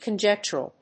発音記号
• / kəndʒéktʃ(ə)rəl(米国英語)
conjectural.mp3